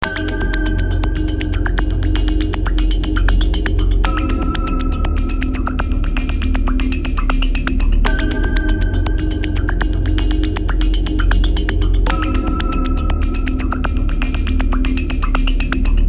滤波向上的低音
描述：在我的电路弯曲的Korg DW6000上做的一些低音效果，过滤器扫起来。 低音FX过滤器电路弯曲
Tag: 140 bpm Electronic Loops Bass Synth Loops 590.89 KB wav Key : Unknown